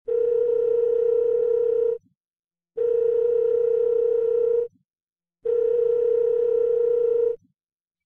Sound Effects
Phone Ringing